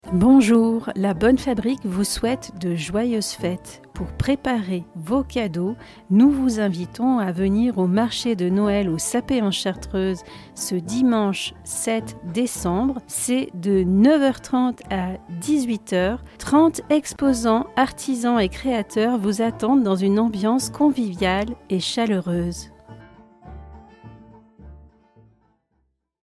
Jingle-Marche-de-Noel-Bonne-Fabrique-MP3.mp3